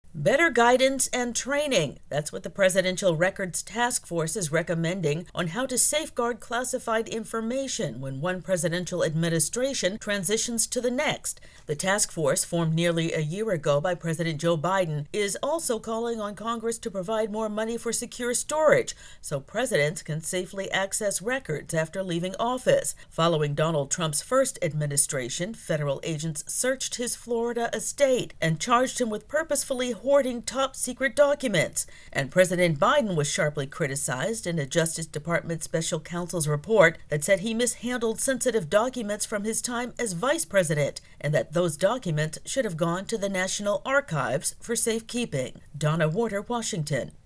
As President Joe Biden packs up to leave the White House, a federal task force has released recommendations on how to help avoid the mishandling of classified documents by a presidential administration. AP correspondent